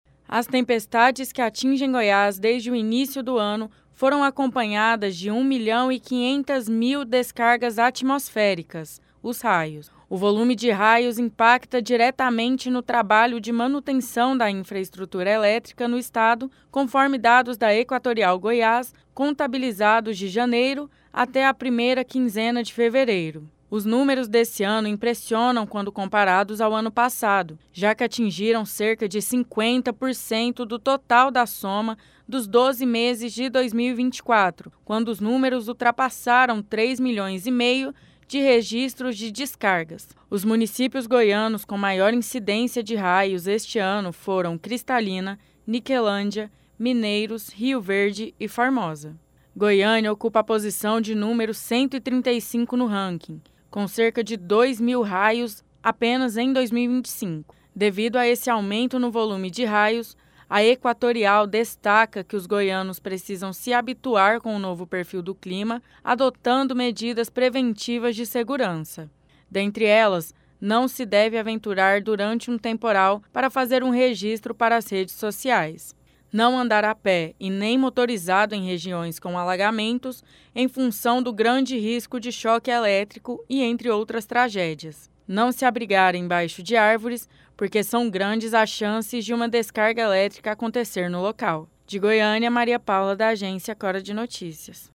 Repórter